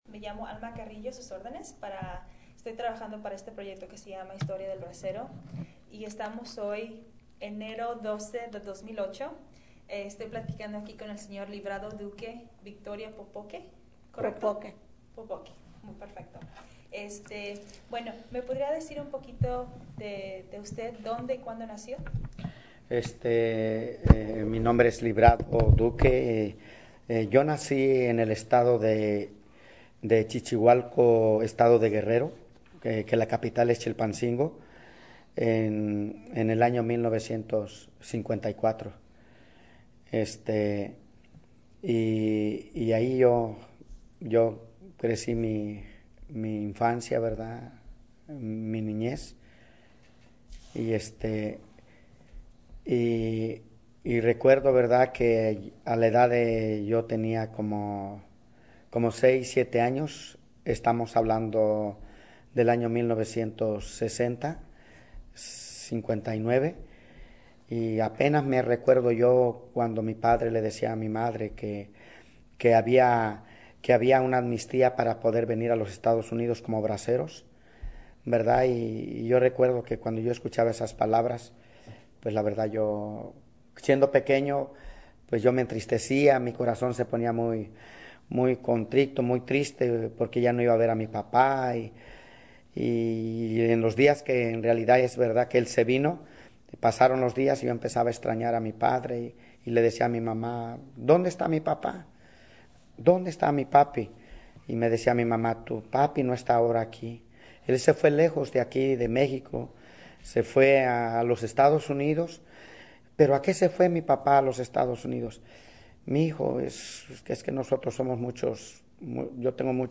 Location Tolleson, Arizona